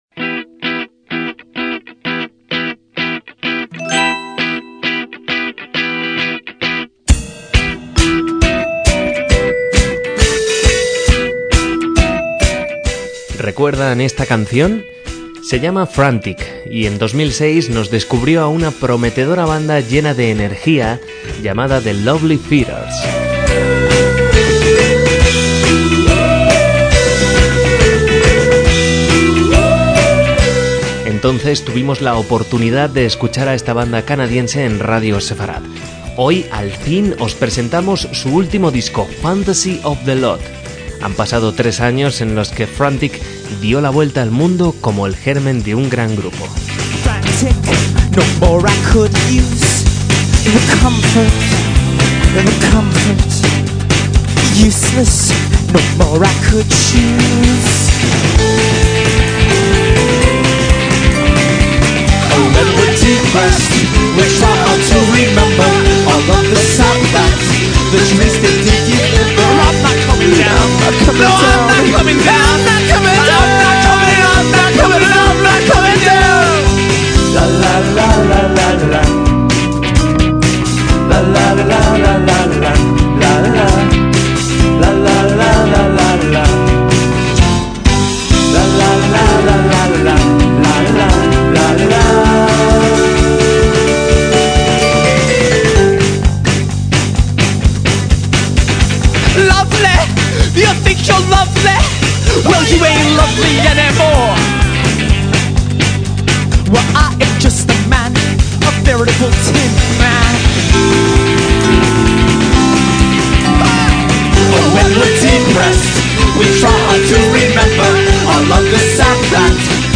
Su estilo recuerda al de grupos como Talking Heads.